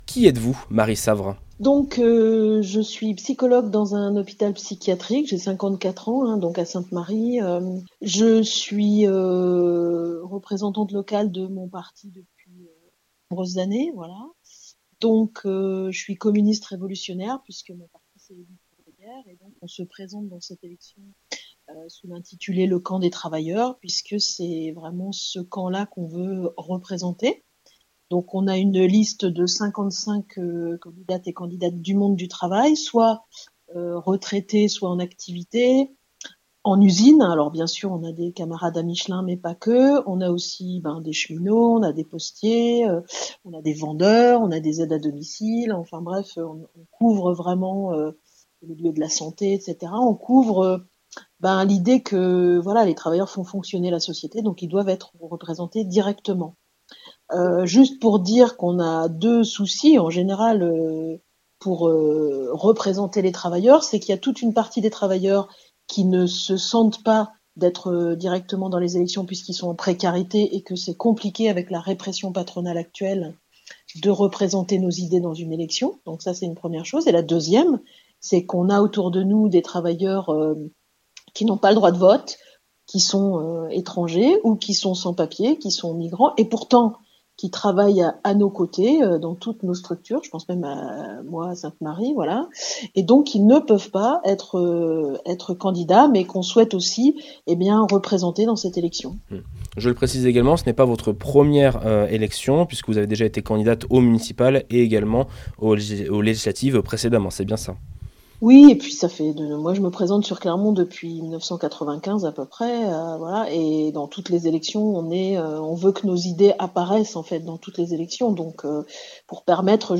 Ce dimanche 15 mars, c'est le premier tour des élections municipales à Clermont-Ferrand. Nous nous sommes entretenu avec les six candidats qui souhaitent briguer la mairie Clermontoise jusqu'en 2033.